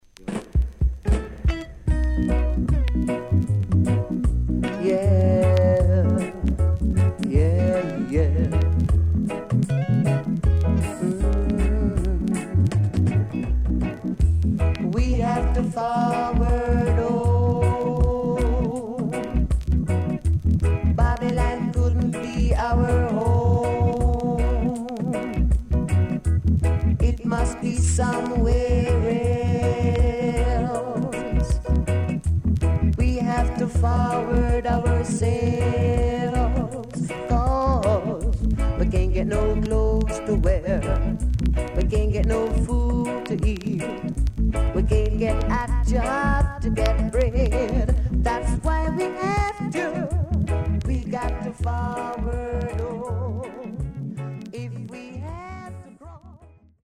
CONDITION SIDE A:VG(OK)〜VG+
SIDE A:所々チリノイズがあり、少しプチノイズ入ります。
SIDE B:所々チリノイズがあり、少しプチノイズ入ります。